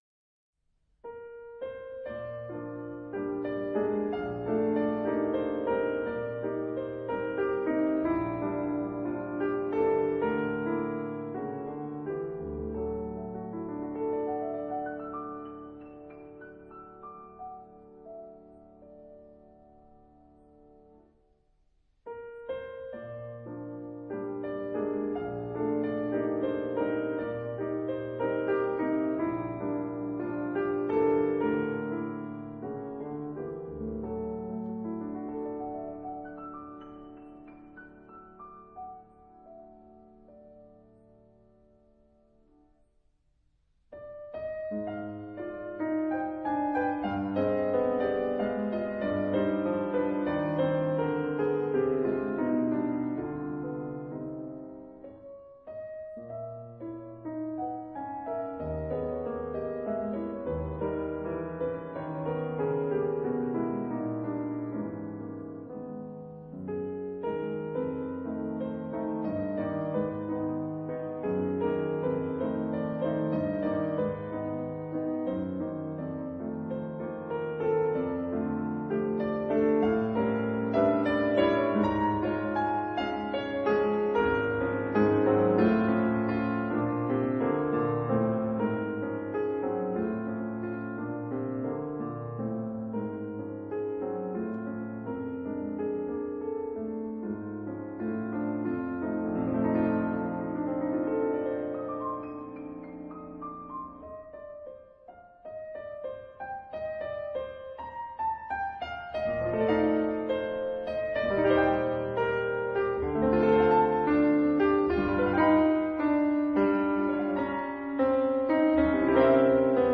反而，收斂一下，帶了點陽光的感覺進來。